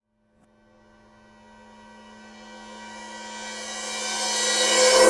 grenade
big environmental-sounds-research explosion grenade processed remix sound-effects war sound effect free sound royalty free Sound Effects